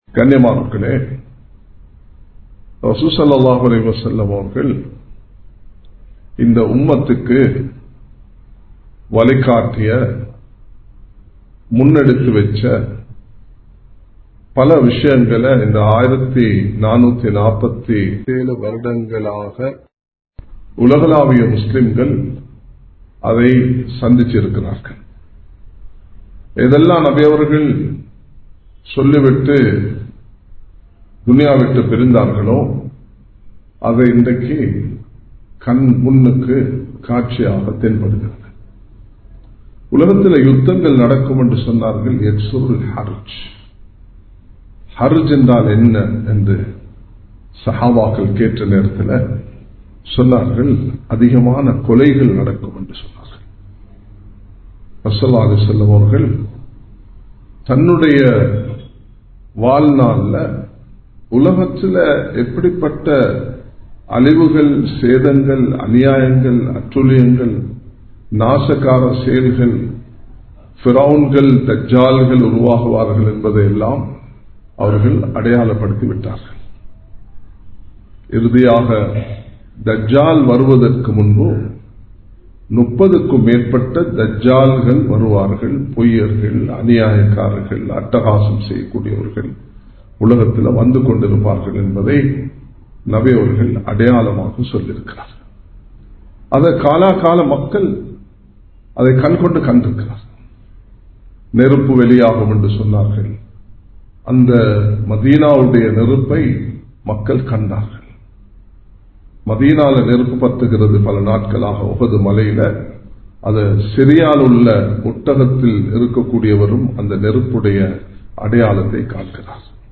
கியாமத் நாளின் அடையாளங்கள் | Audio Bayans | All Ceylon Muslim Youth Community | Addalaichenai
Samman Kottu Jumua Masjith (Red Masjith)